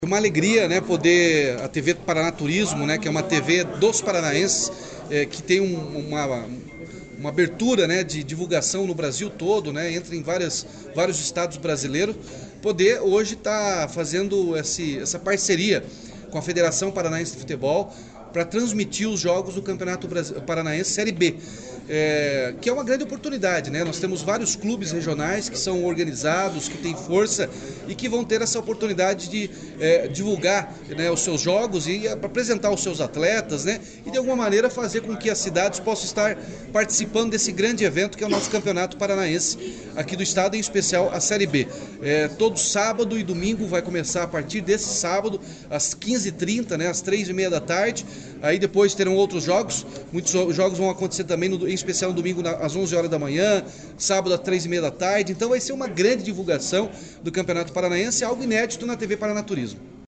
Sonora do governador Ratinho Junior sobre a transmissão da segunda divisão do Campeonato Paranaense de 2023 pela TV Paraná Turismo